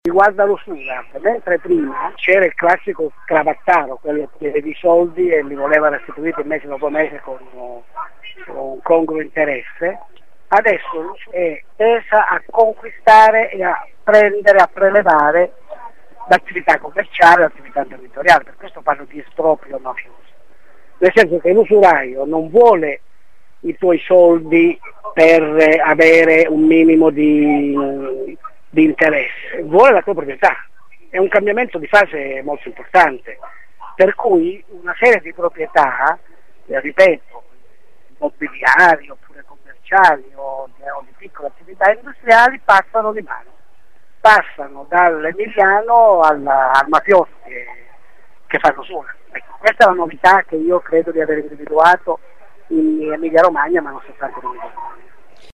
Un vero e proprio “esproprio mafioso” come ci spiega l’ex senatore Enzo Ciconte, ex membro della commissione antimafia.